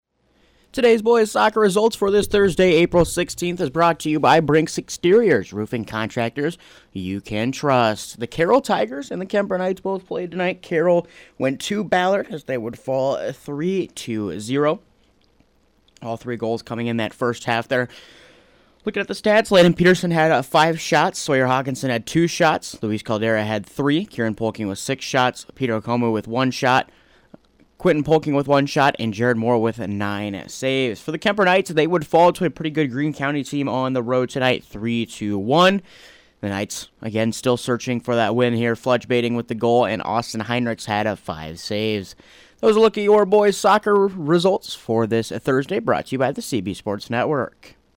Below is an Audio Recap of boys’ soccer results from Thursday, April 16th